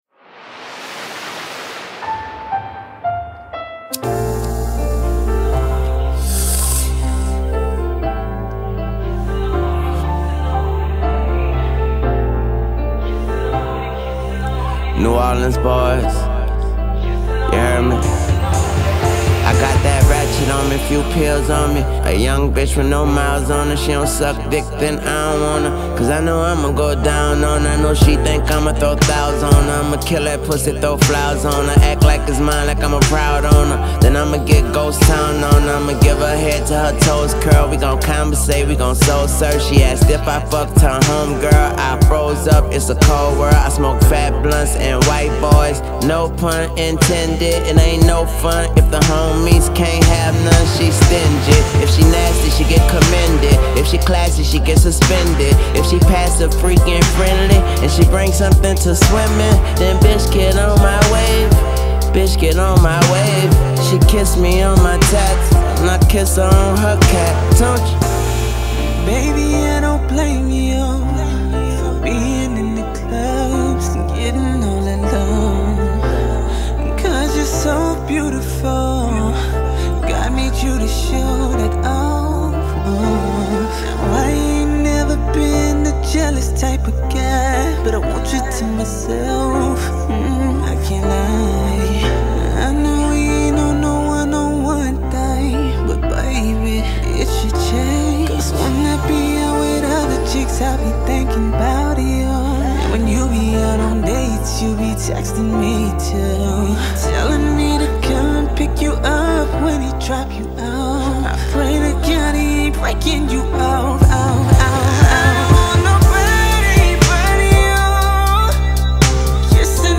soft raps